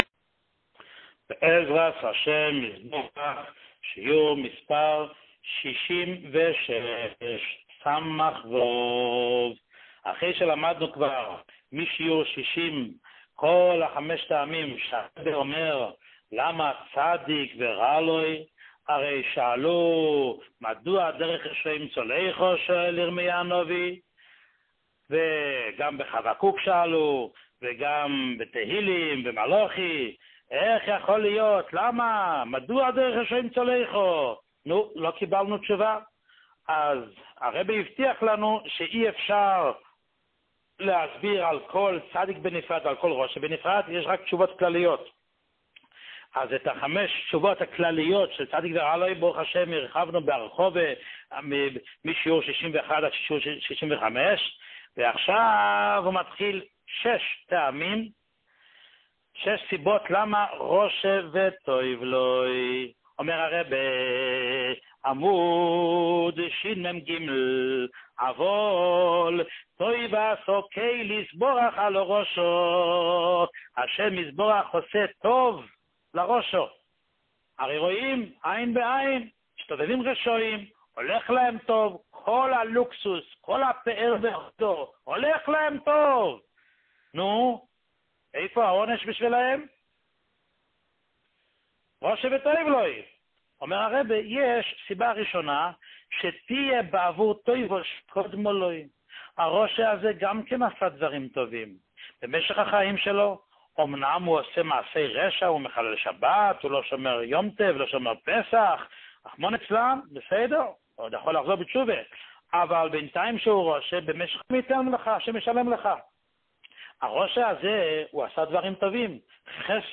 שיעור 66